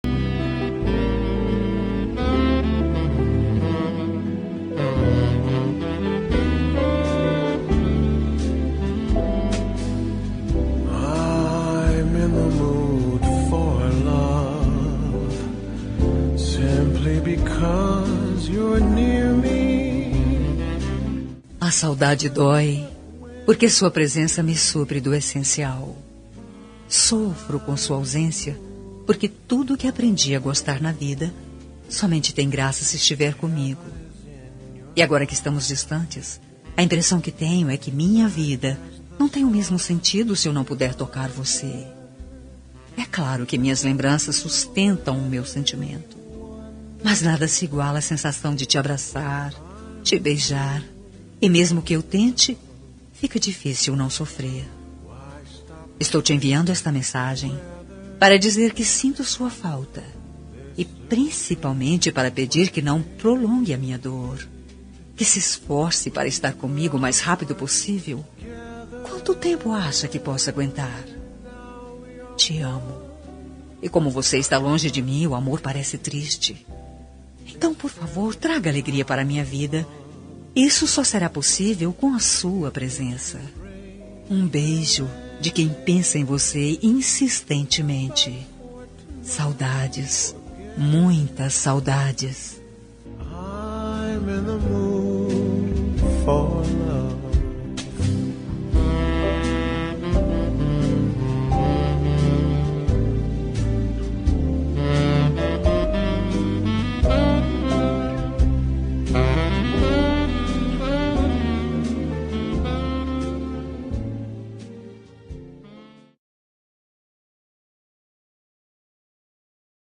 Telemensagem de Saudades – Voz Feminina – Cód: 438